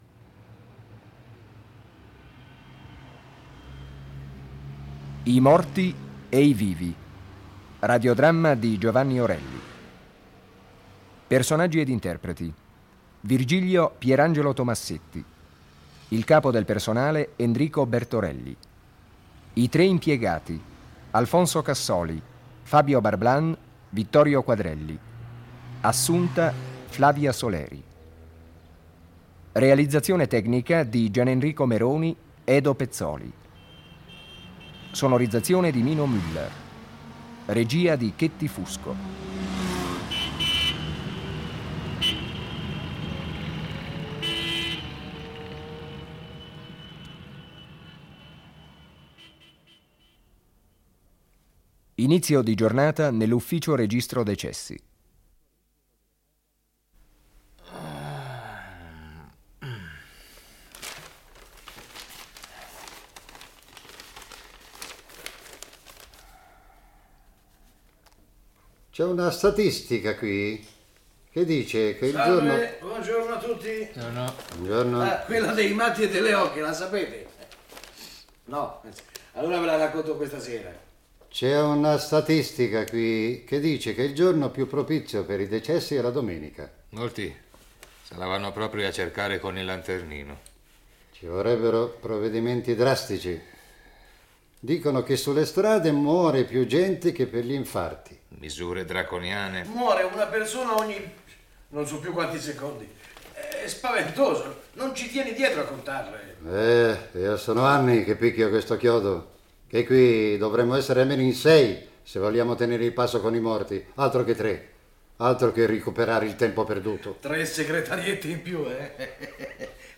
"I morti e i vivi" - Radioteatro (1977)